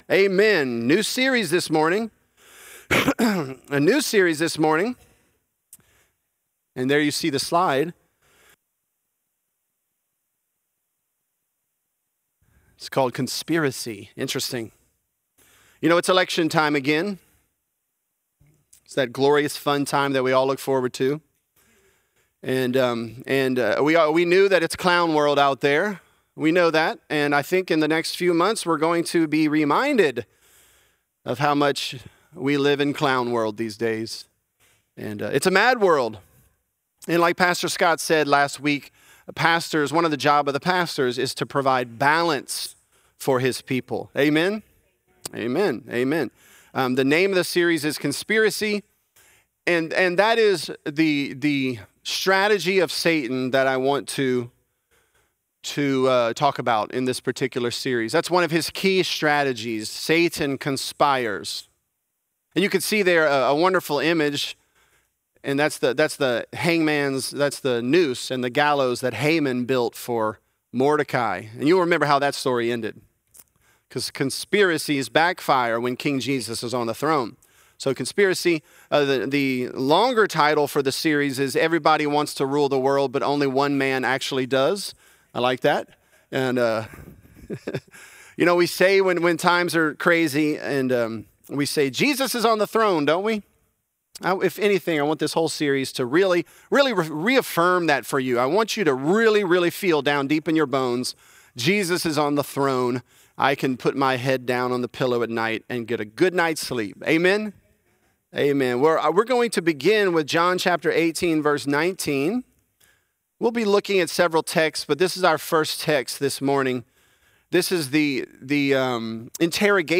Conspiracy: Conspiracy & Christ | Lafayette - Sermon (John 18)